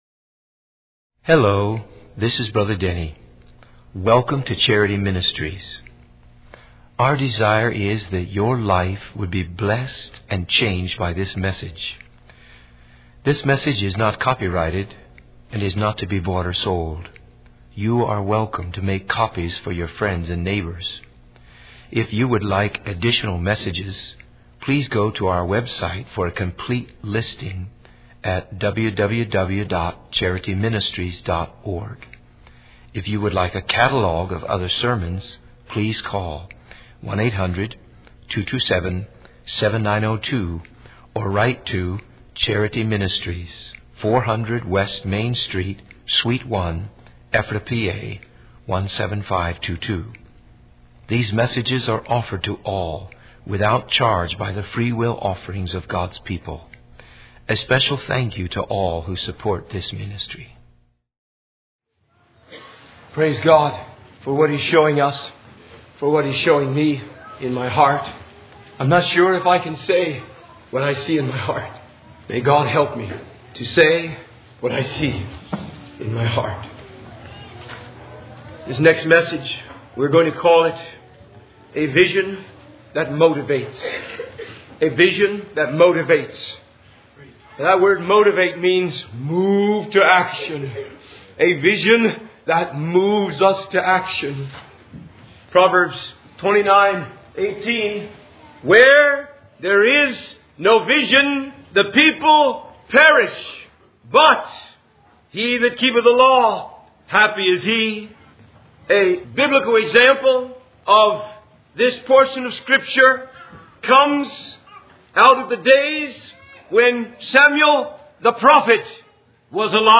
In this sermon, the speaker emphasizes the importance of having a vision that sustains and motivates us in our spiritual journey.